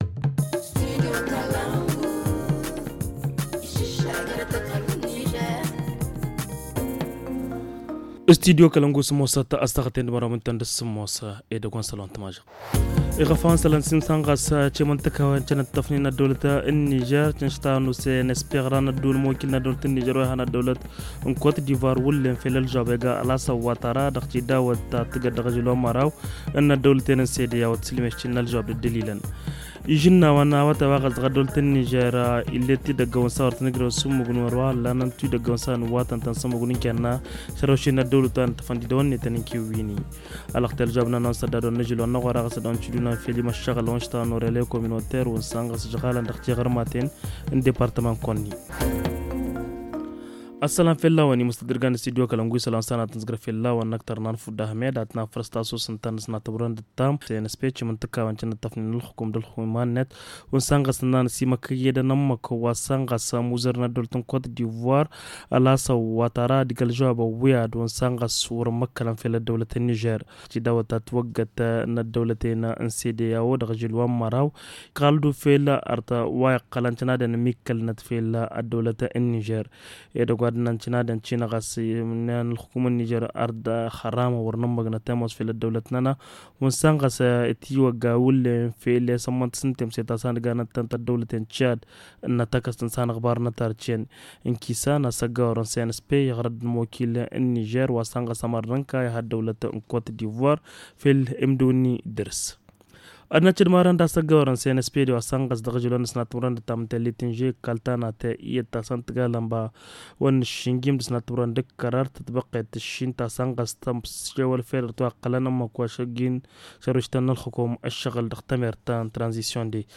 Le journal du 15 août 2023 - Studio Kalangou - Au rythme du Niger